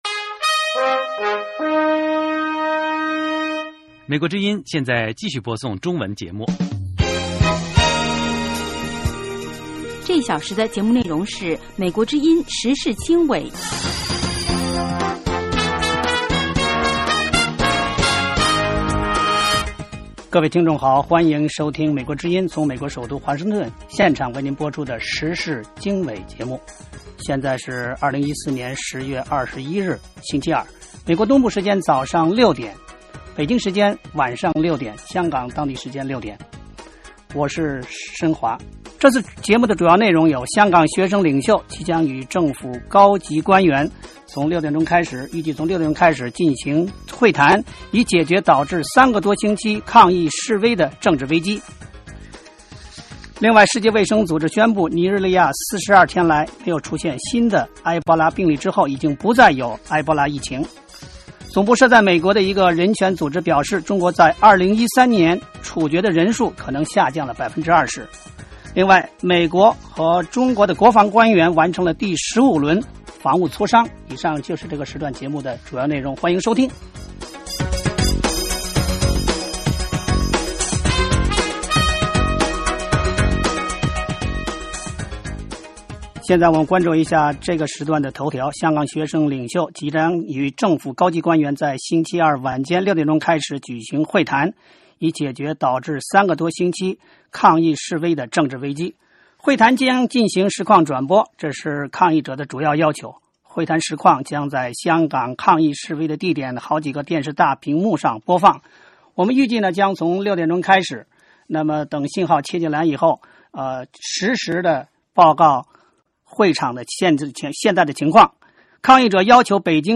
香港政府代表与香港学联代表于北京时间10月21日下午六时到八时举行首次对话。美国之音广播对这次对话进行实时转播，并邀请专家进行现场点评。